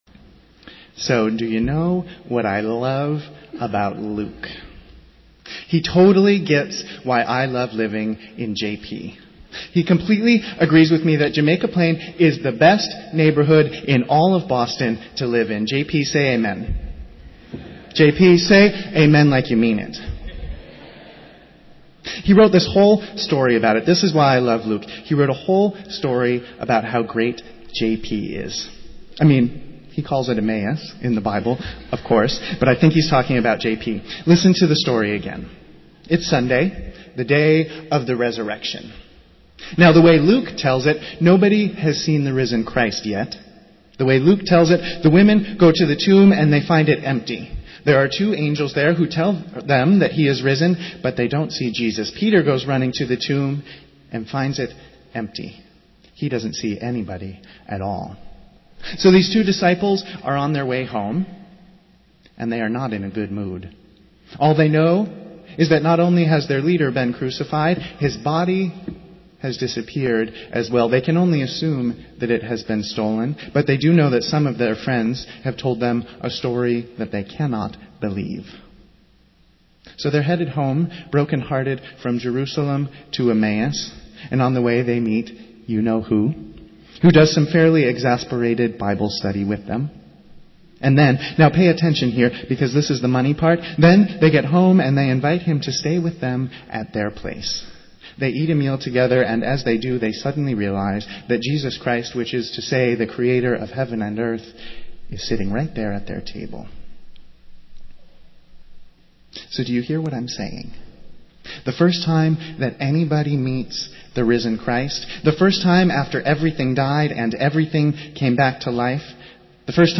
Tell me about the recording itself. Festival Worship - Second Sunday of Easter